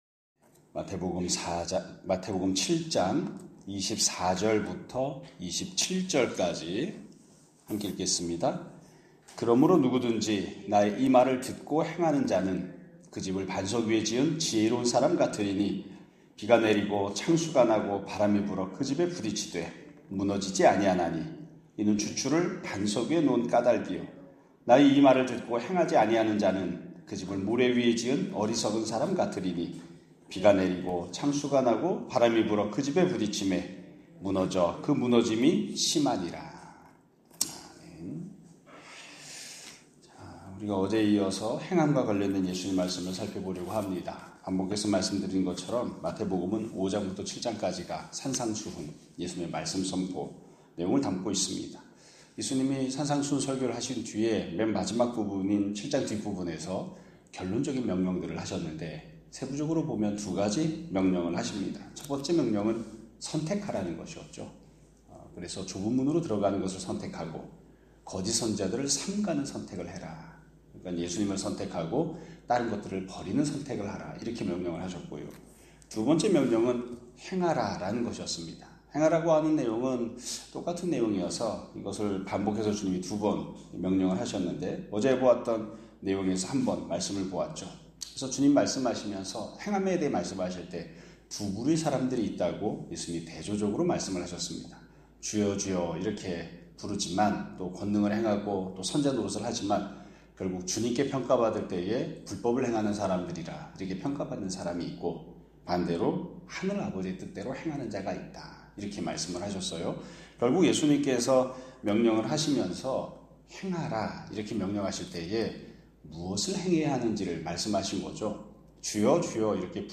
2025년 7월 3일(목요 일) <아침예배> 설교입니다.